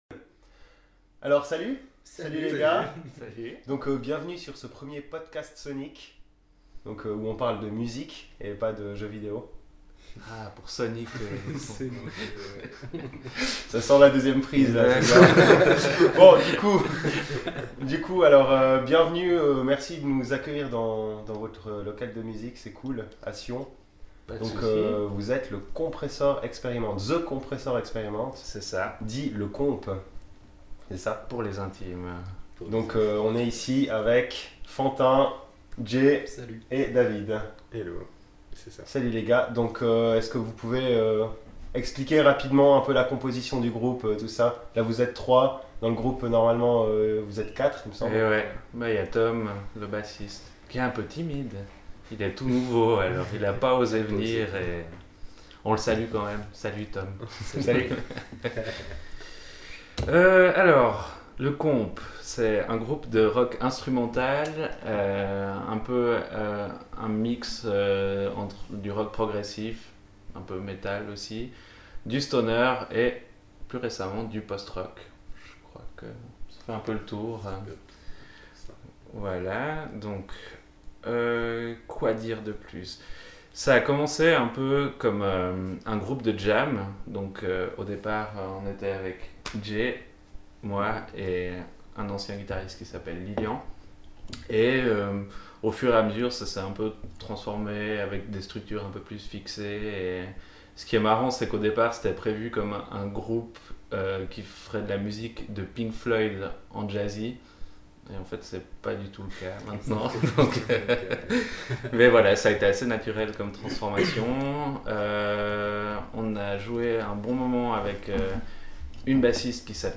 Ambiance cosy, bières et bon son. Petite visite d’Artsonic dans les locaux du Komp’ afin de discuter autour de leur nouvel opus qui s’annonce prometteur.